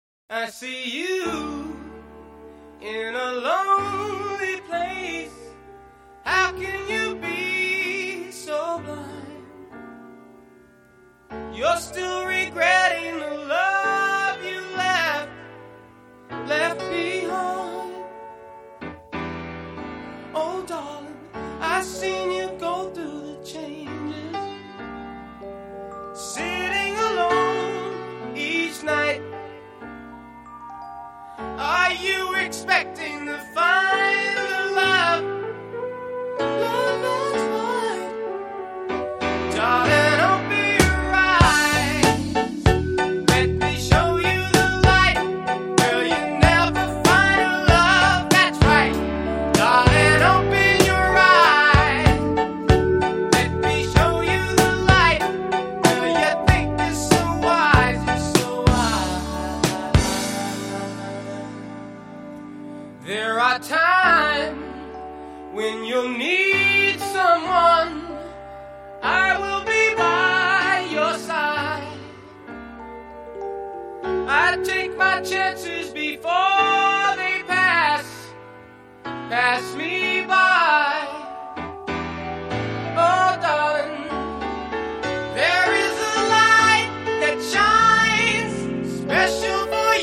The smooth titan of blue-eyed soul